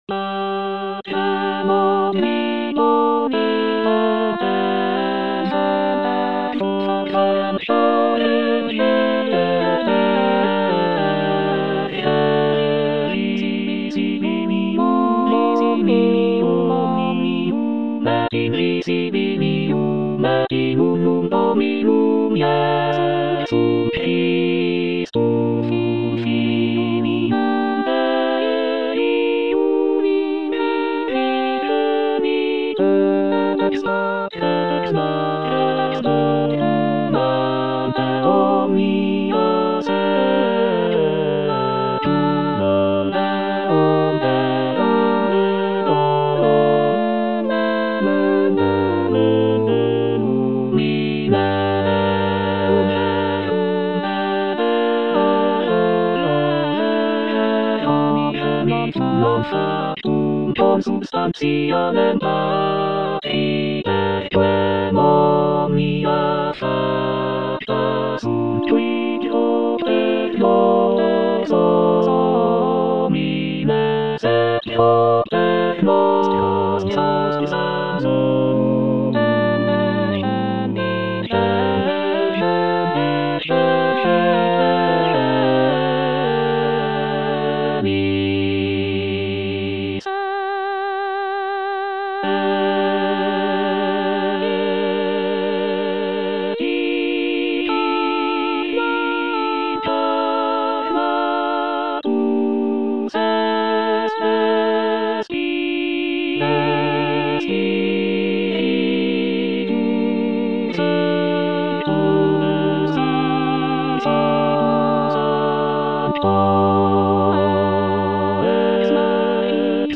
T.L. DE VICTORIA - MISSA "O MAGNUM MYSTERIUM" Credo (All voices) Ads stop: auto-stop
"Missa "O magnum mysterium"" is a choral composition by the Spanish Renaissance composer Tomás Luis de Victoria.
It is renowned for its rich harmonies, expressive melodies, and intricate counterpoint.